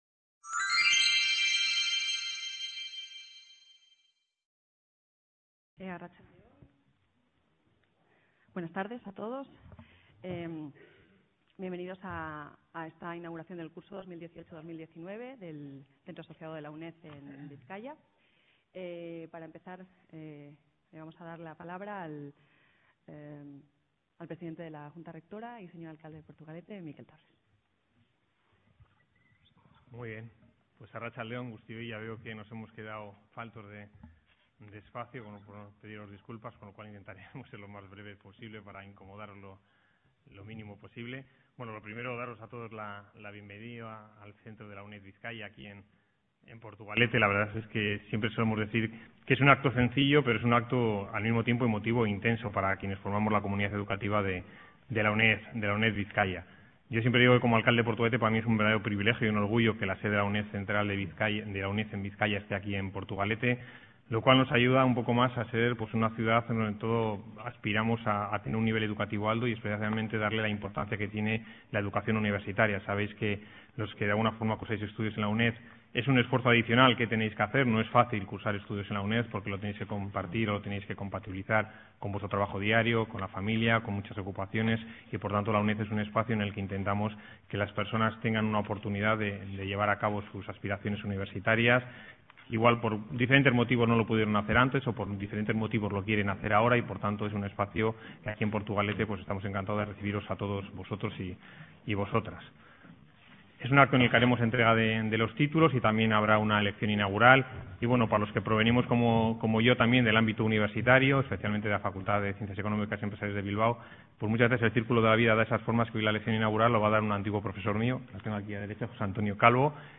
Acto académico de apertura del curso 2018-2019 en el Centro Asociado UNED Bizkaia